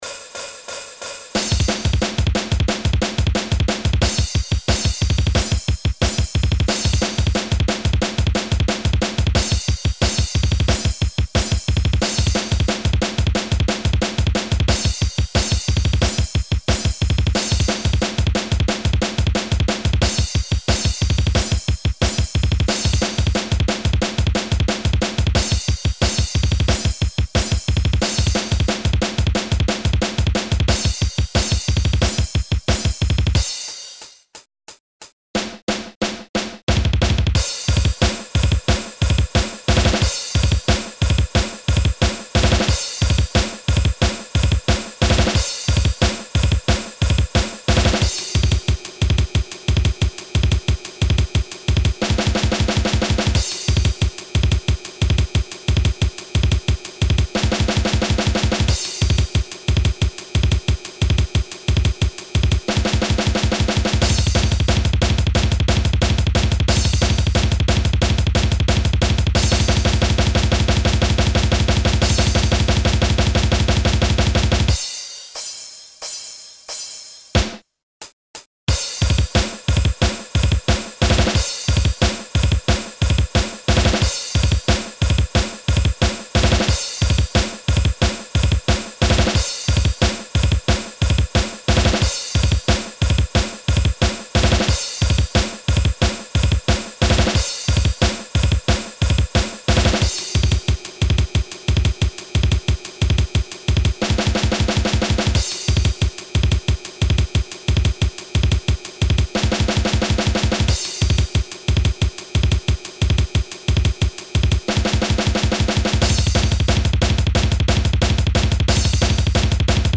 Et pas de réglages : toutes les pistes étaient au même volume, sans effets, et sans balance.
Mais le plus contraignant c'est qu'il n'y avait qu'un seul tempo : 180 bpm.
Voici un exemple de piste de batterie utilisée sur la première démo (en mono donc) :
deathwing--under-the-wings-of-fear--drums.mp3